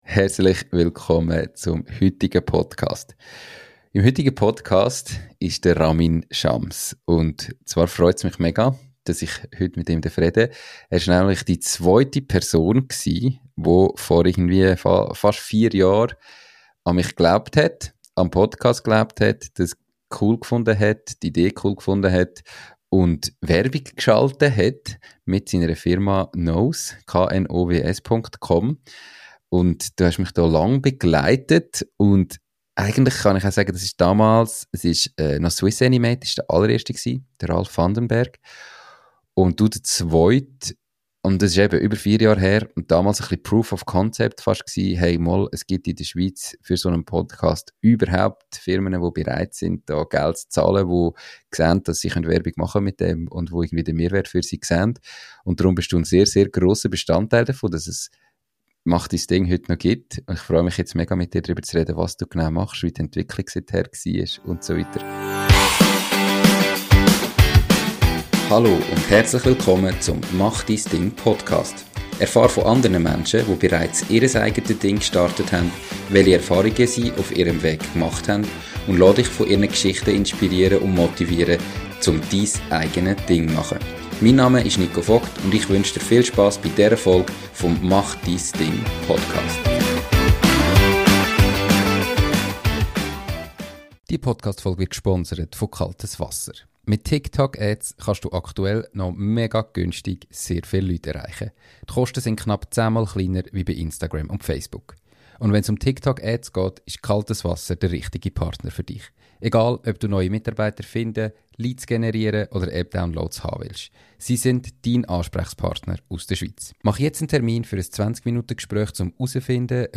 Erfolgreiche Unternehmer und Selbstständige aus der Schweiz erzählen dir ihre Geschichte. Sie zeigen dir, welche Stolpersteine Sie bewältigen mussten, welche Erfolge und Misserfolge sie hatten, und geben dir Tipps, wie du es besser machen kannst!